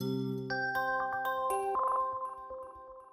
incomingcall.wav